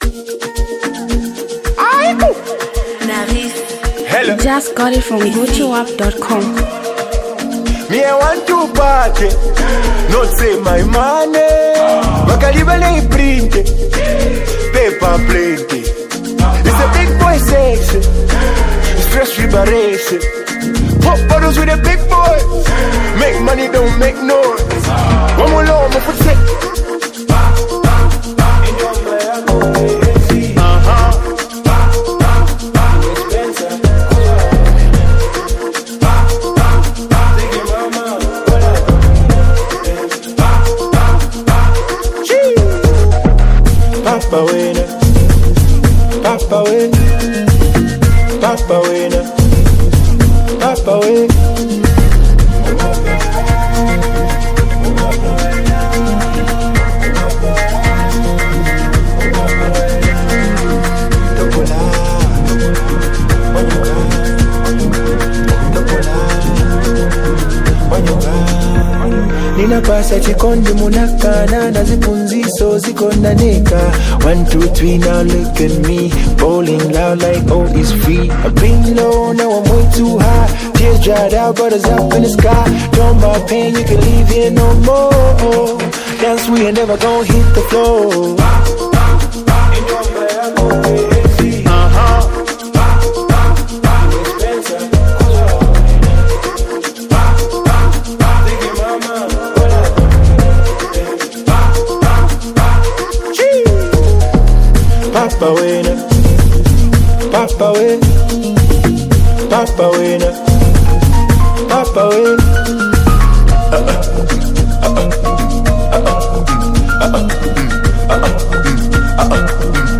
a Zambian prominent afro-dancehall hit Maker
ghetto Vibing Song